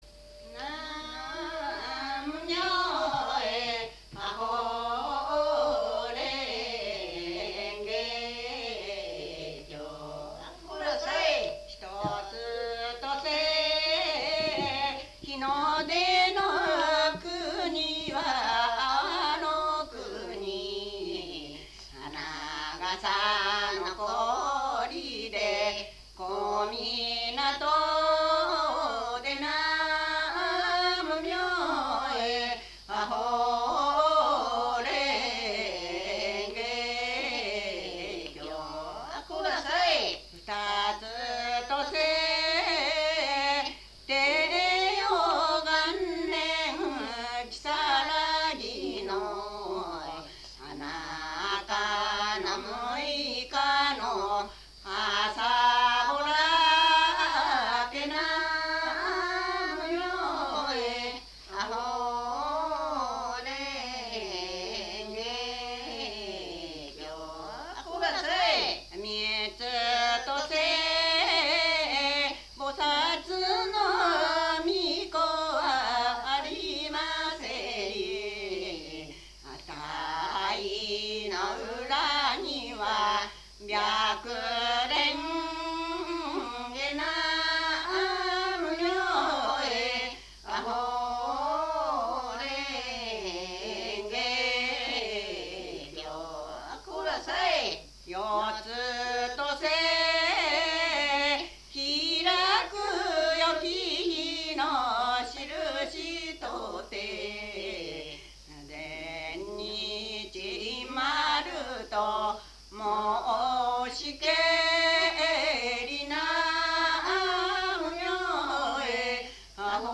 82 60 15 勝浦市 　 川津
題目おどり 座興歌